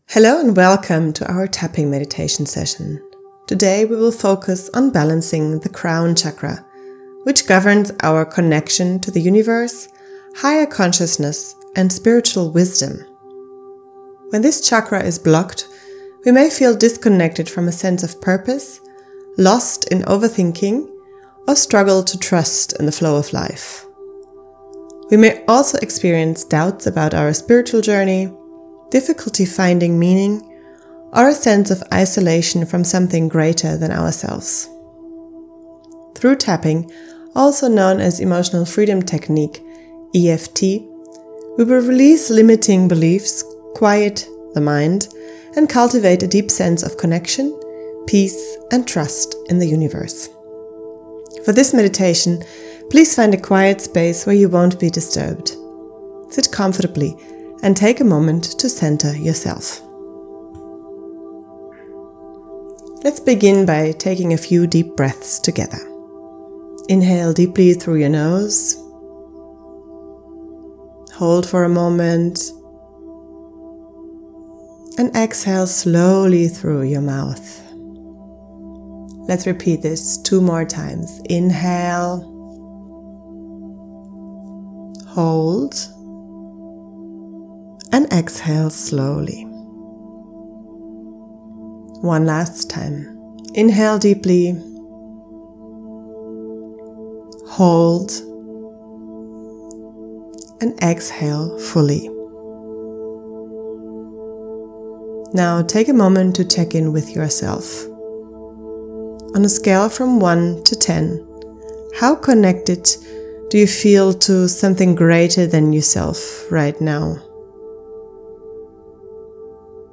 Crown-tapping-meditation.mp3